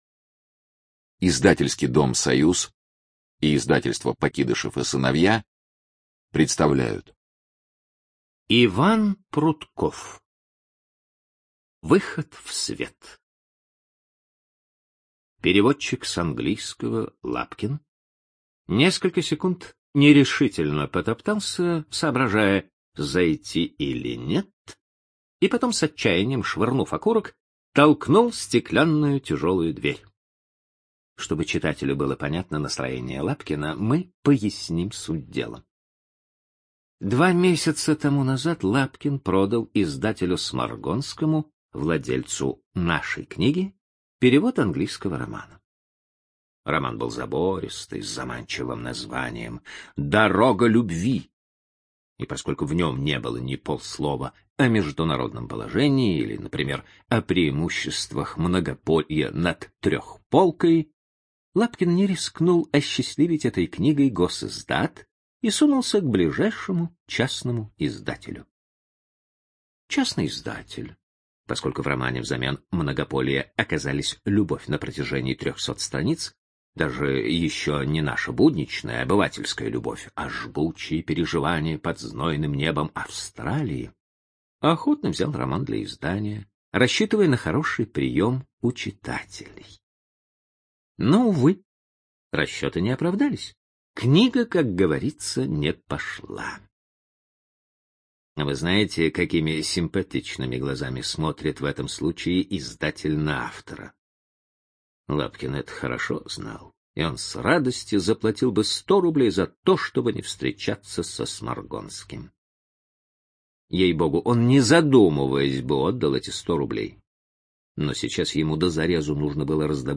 Студия звукозаписиСоюз